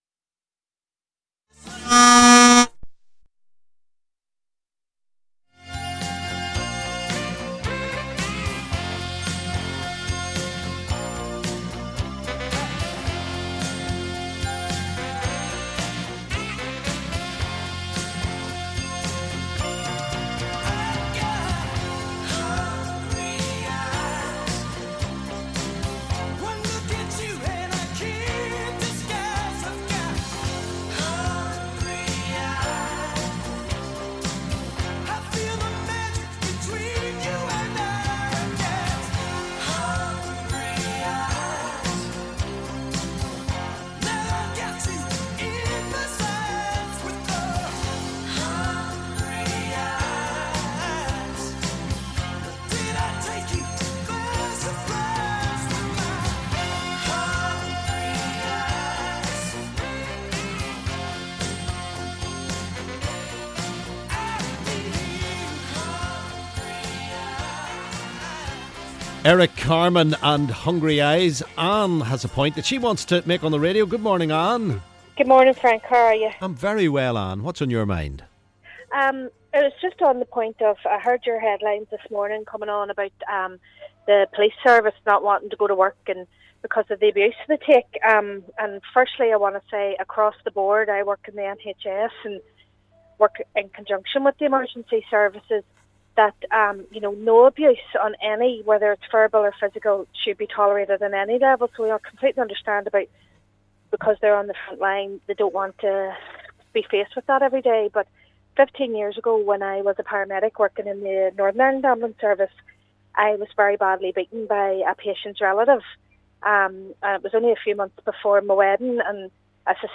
LISTEN: Paramedic describes assault while on duty